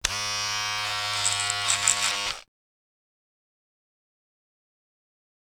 shave.wav